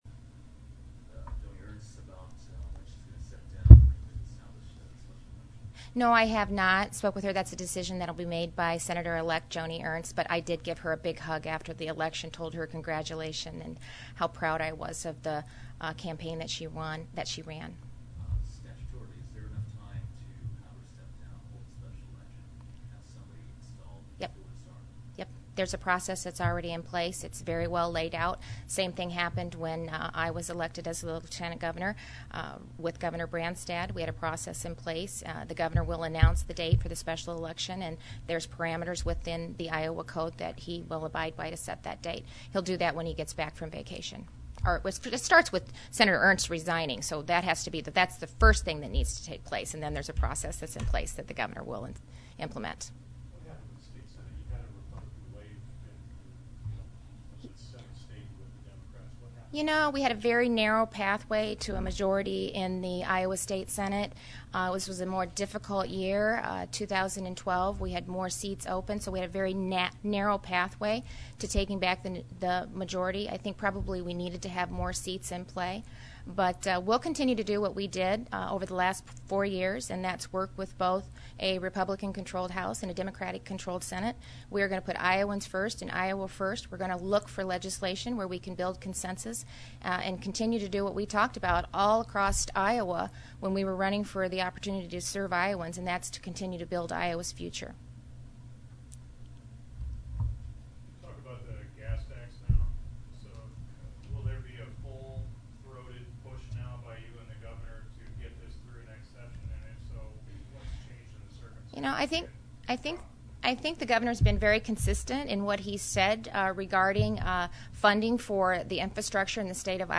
Lieutenant Governor Kim Reynolds met with the media alone today with the governor out of the country for a post-election vacation.
Audio: Kim Reynolds news conference 9:00